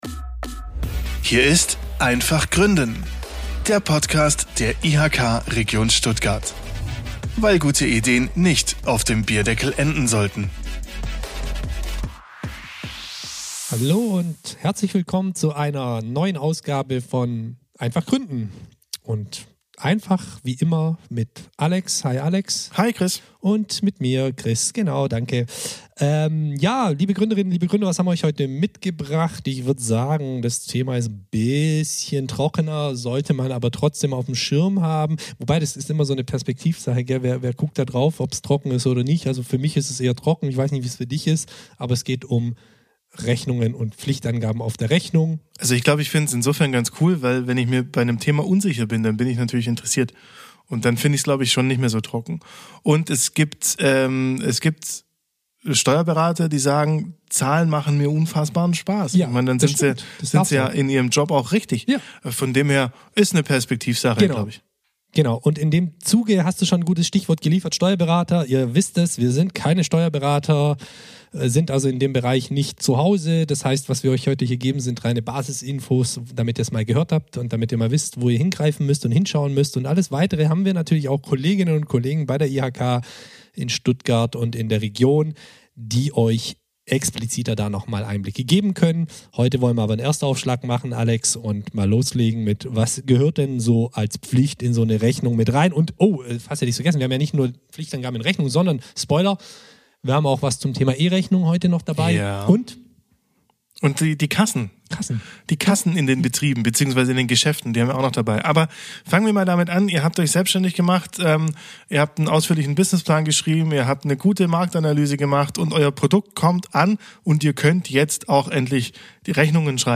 Und keine Angst, es klingt etwas trocken, doch wir arbeiten natürlich wieder mit Beispielen und führen locker durch die Themen.